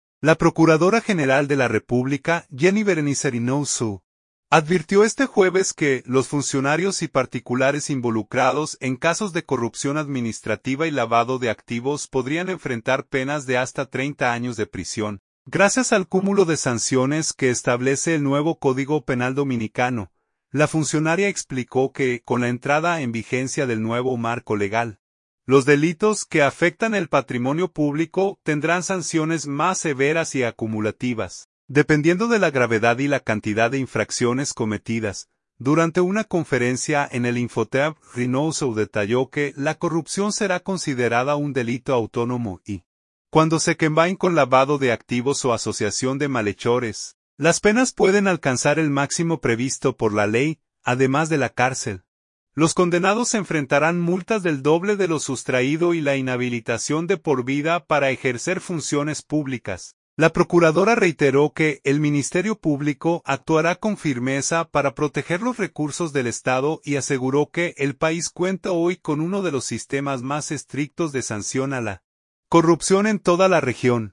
Durante una conferencia en el INFOTEP, Reynoso detalló que la corrupción será considerada un delito autónomo y, cuando se combine con lavado de activos o asociación de malhechores, las penas pueden alcanzar el máximo previsto por la ley.